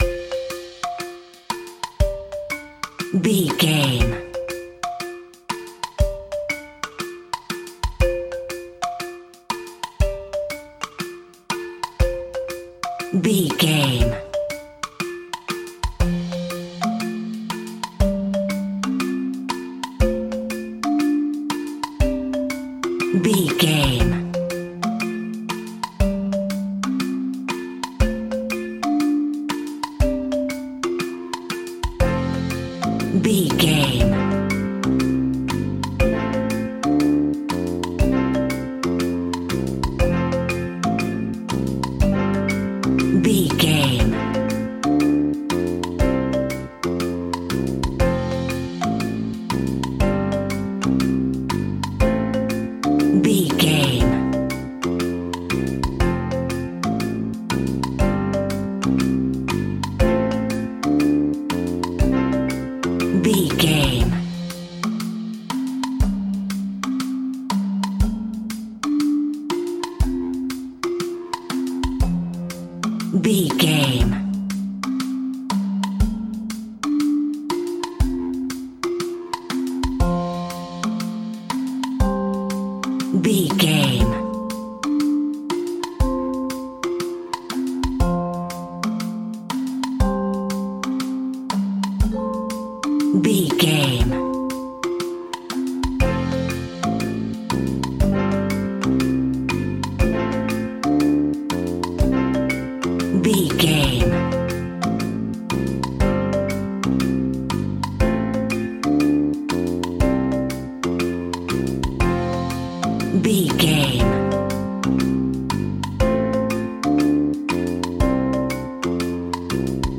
Uplifting
Ionian/Major
childrens music
drums
bass guitar
electric guitar
piano
hammond organ